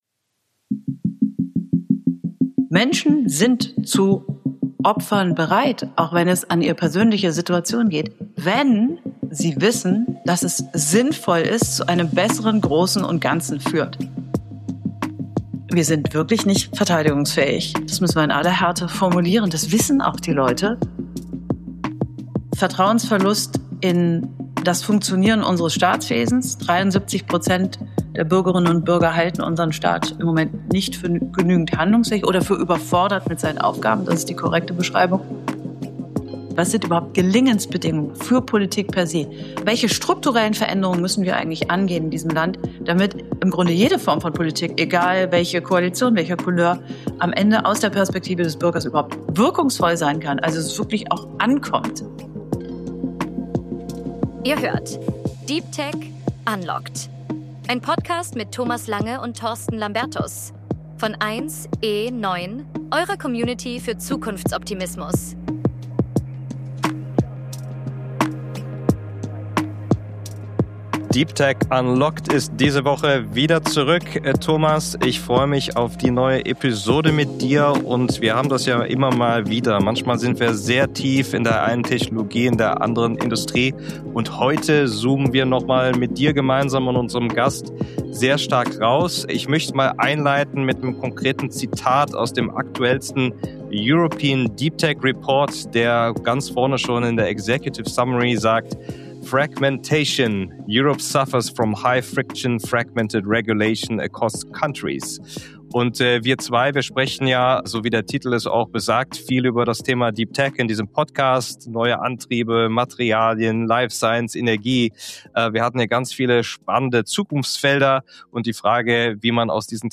Das Gespräch mit ihr geht der Frage nach, warum technologische Zukunftsfähigkeit ohne funktionierende staatliche Strukturen nicht möglich ist. Es geht um Vertrauensverlust und Reformstau, um Bürokratie und fehlende Umsetzungskraft – aber auch um konkrete Lösungsansätze: von einfacheren Gesetzen über Experimentierklauseln bis hin zu einem neuen Verhältnis zwischen Staat, Wirtschaft und Bürgern.